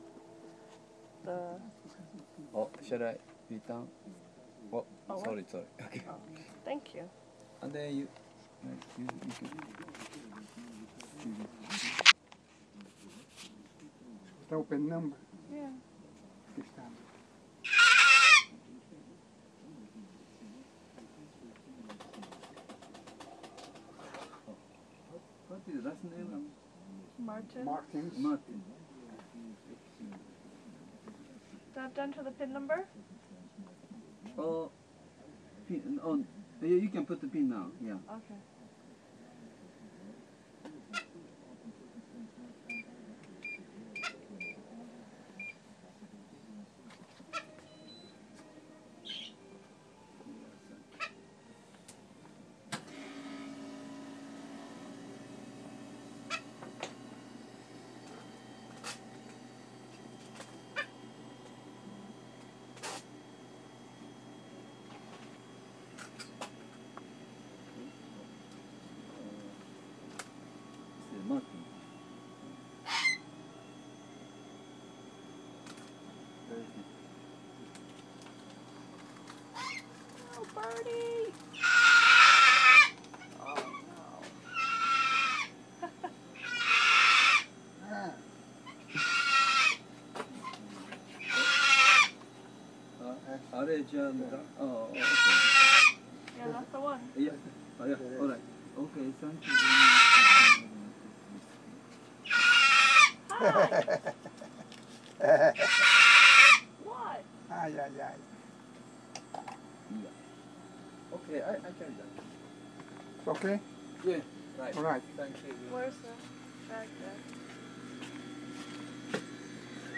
What does this screechy bird want?